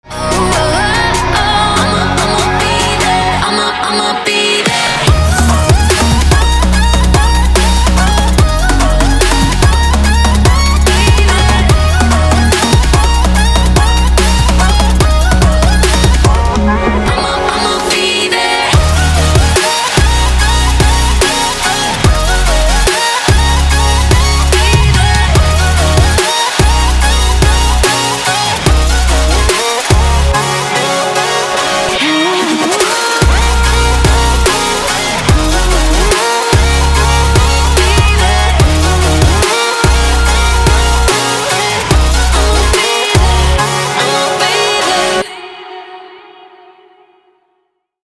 Electronic
Trap
future bass
vocal